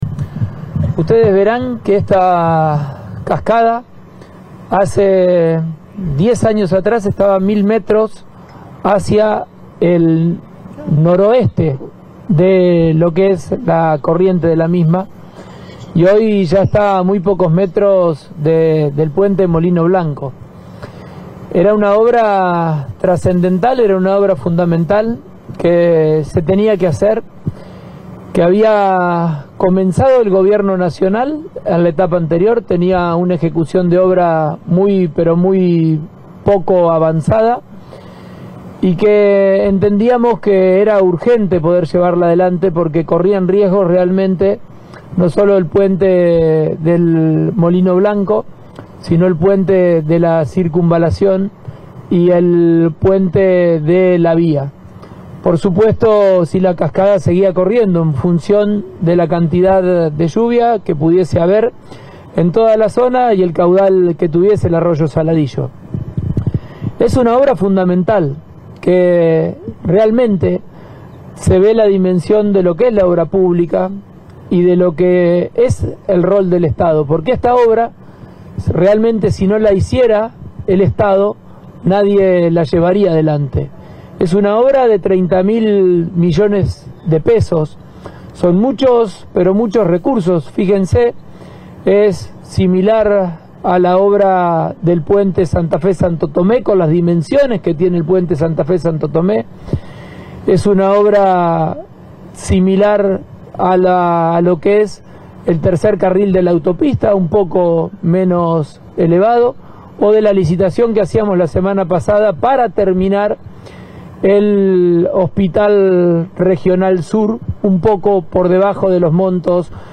El gobernador santafesino recorrió los trabajos que se ejecutan sobre la cascada del arroyo Saladillo, en el límite entre Rosario y Villa Gobernador Gálvez.
Declaraciones de Pullaro y Enrico